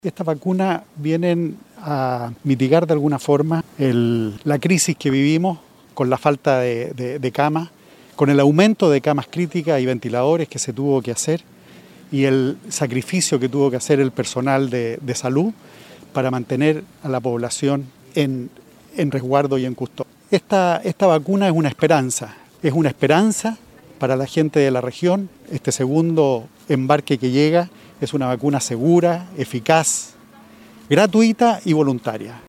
Manifestó el intendente Carlos Geisse que estas vacunas llegan a mitigar de alguna forma la crisis por la falta de camas, el aumento de camas críticas y ventiladores.
01-CARLOS-GEISSE-INTENDENTE.mp3